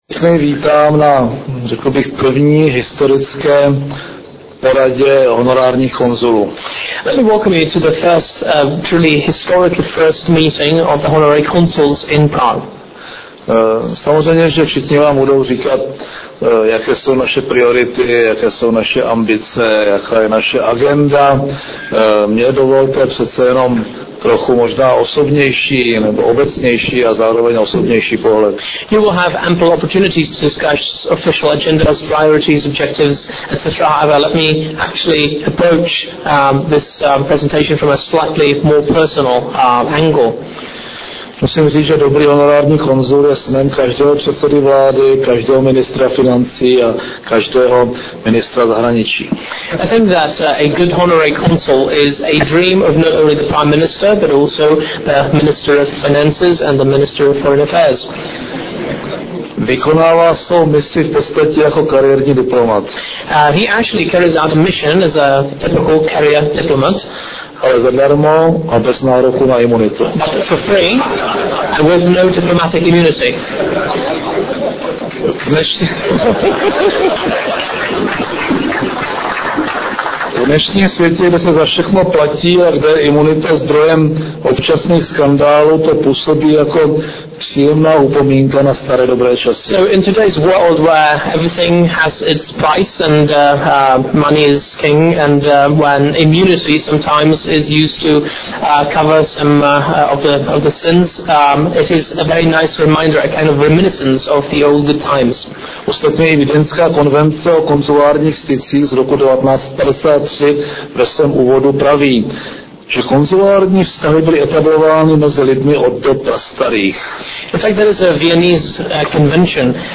Projev premiéra M. Topolánka u příležitosti setkání s honorárními konzuly a vybranými velvyslanci ČR v zahraničí 2. 6. 2008